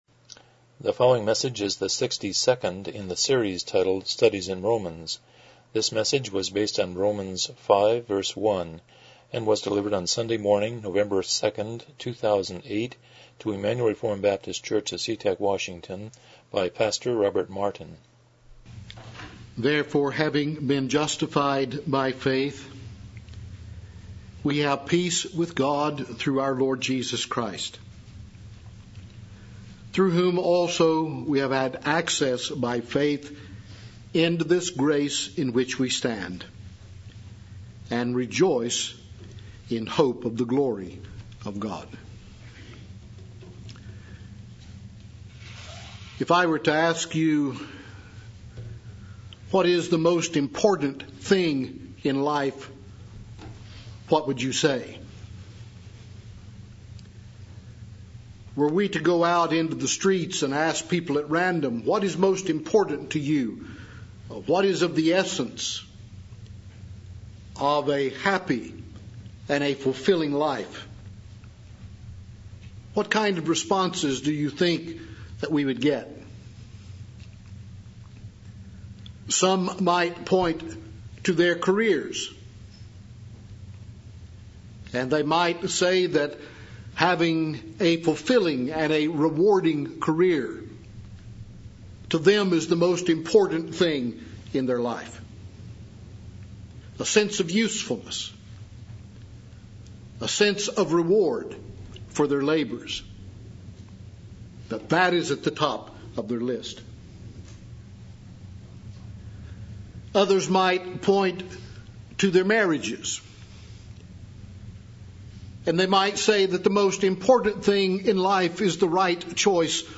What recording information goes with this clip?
Romans 5:1 Service Type: Morning Worship « 10 The Uniqueness of Man